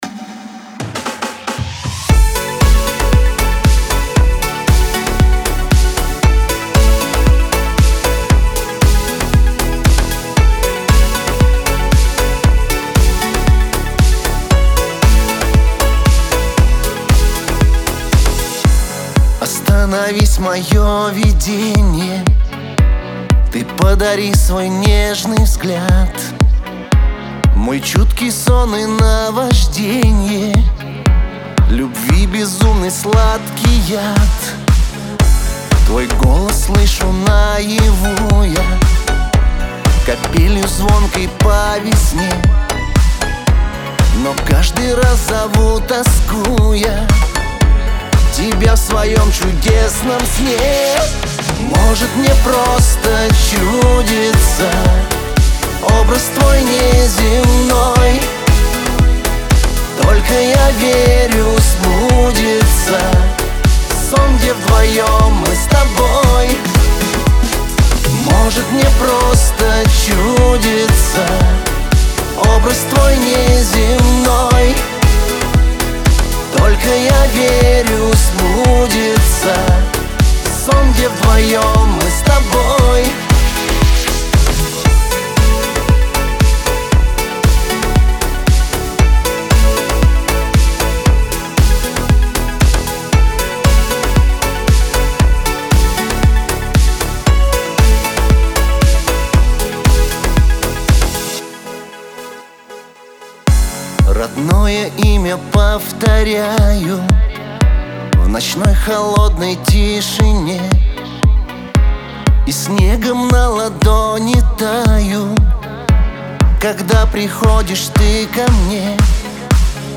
Лирика , pop
эстрада